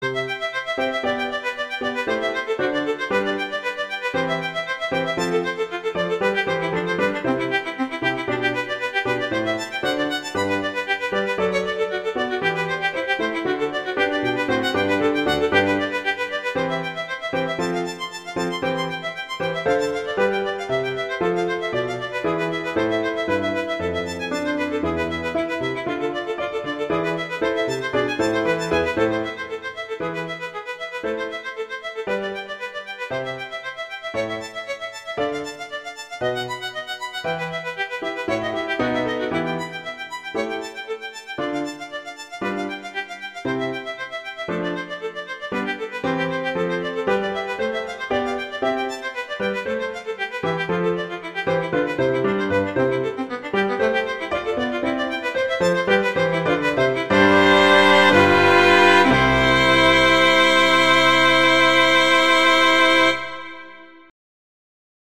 violin and piano
classical
Allegro